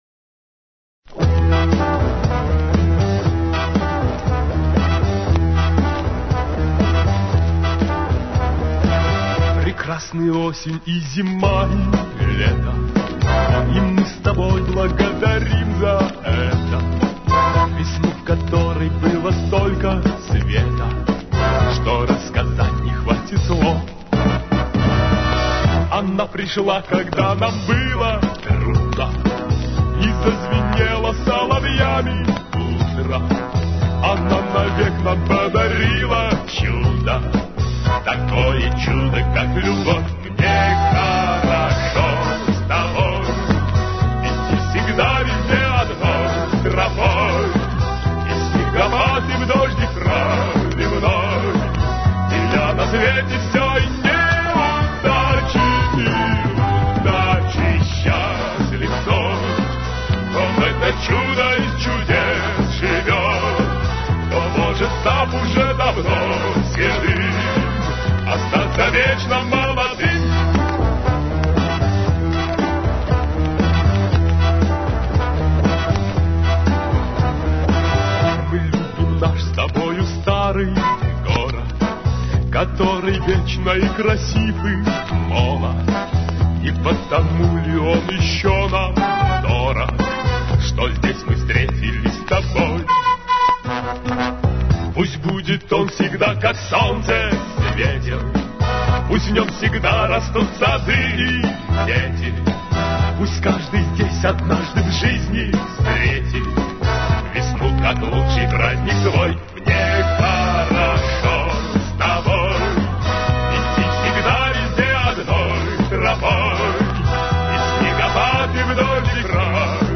очистил маленько от скрипов...